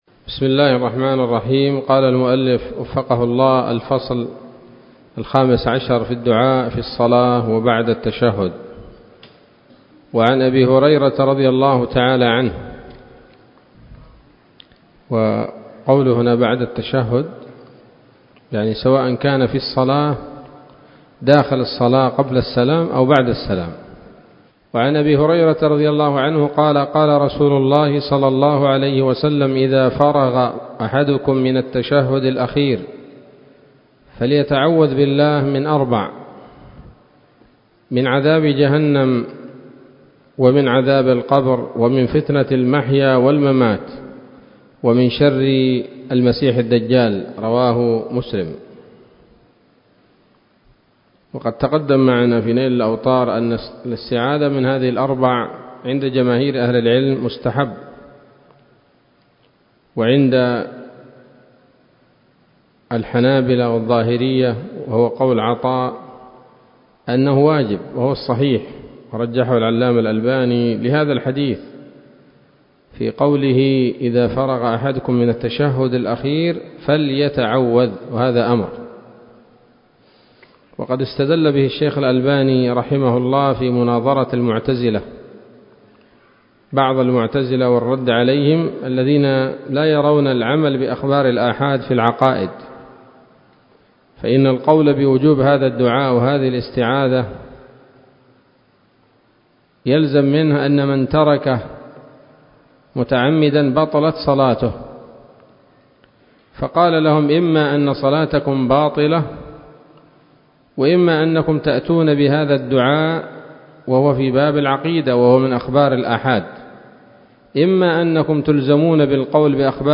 الدرس السادس والعشرون من رياض الأبرار من صحيح الأذكار